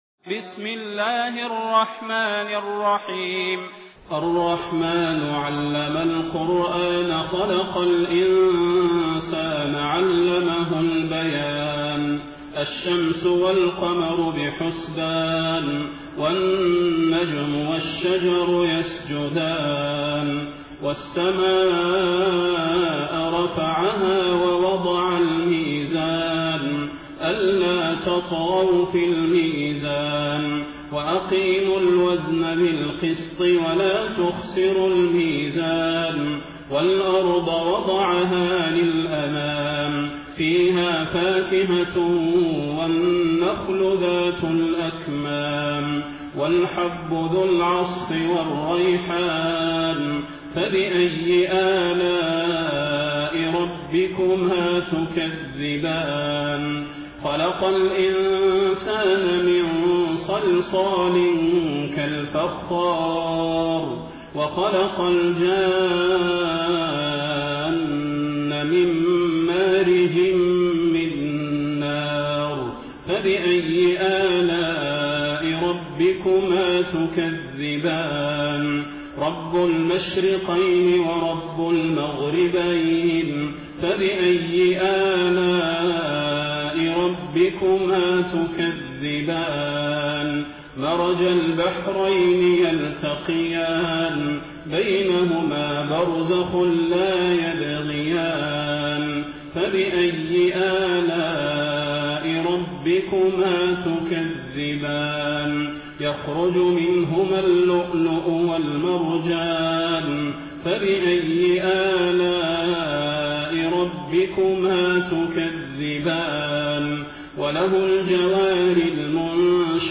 taraweeh-1433-madina